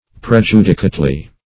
prejudicately - definition of prejudicately - synonyms, pronunciation, spelling from Free Dictionary Search Result for " prejudicately" : The Collaborative International Dictionary of English v.0.48: Prejudicately \Pre*ju"di*cate*ly\, adv.
prejudicately.mp3